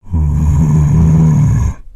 Sound Effects
Zombie Groan